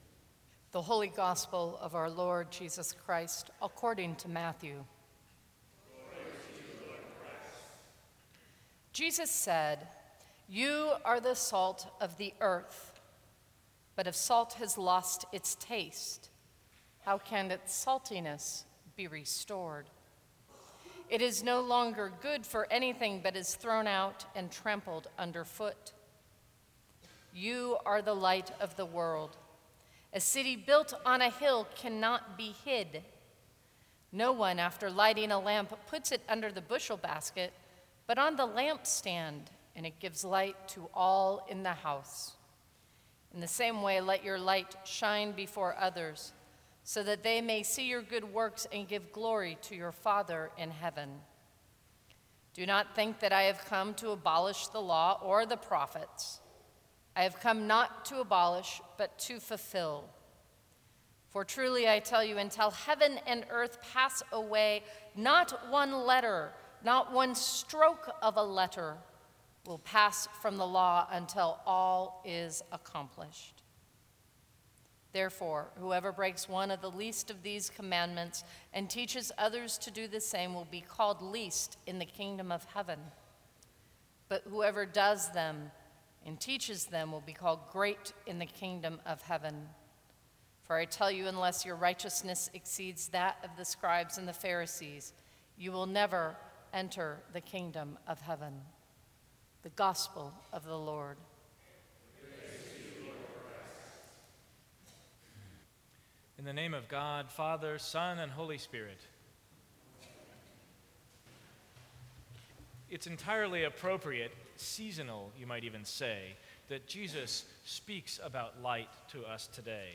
Sermons from St. Cross Episcopal Church February 9, 2014 Salt and Light Feb 12 2014 | 00:10:20 1x 00:00 / 00:10:20 Subscribe Share Apple Podcasts Spotify Overcast RSS Feed Share Link Embed